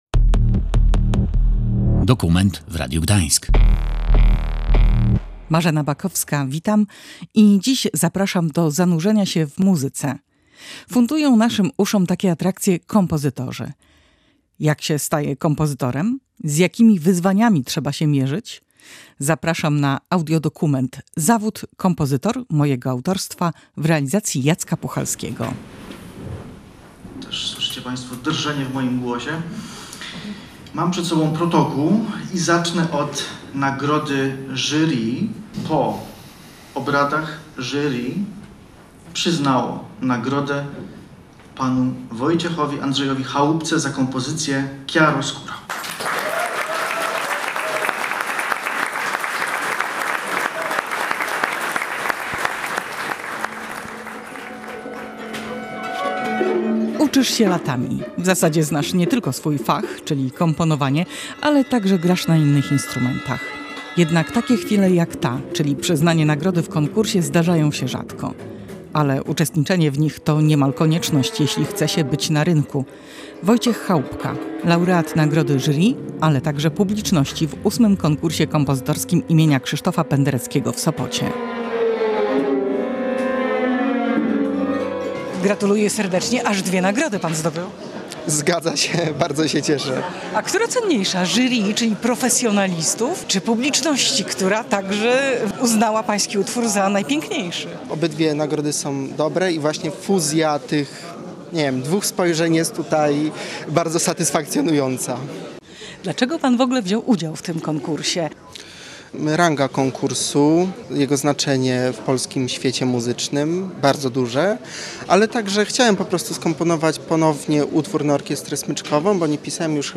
Posłuchaj dokumentu radiowego „Zawód kompozytor”: